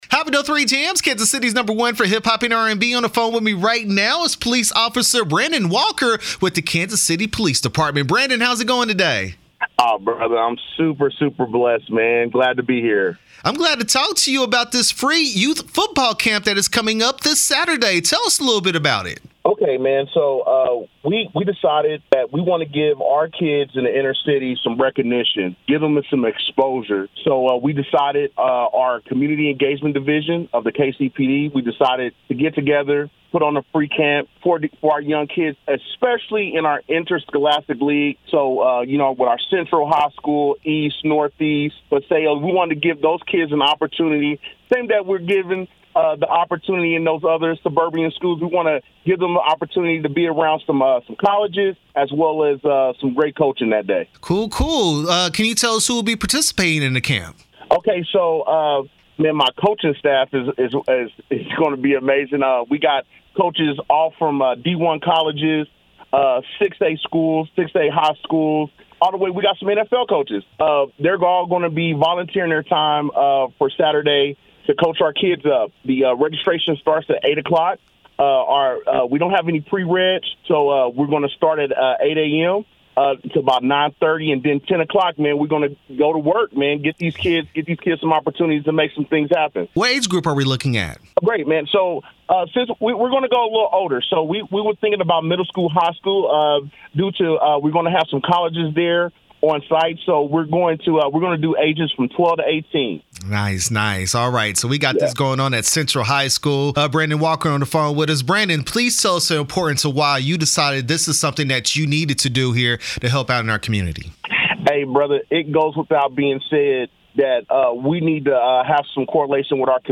KCPD Youth Football Camp interview 6/29/23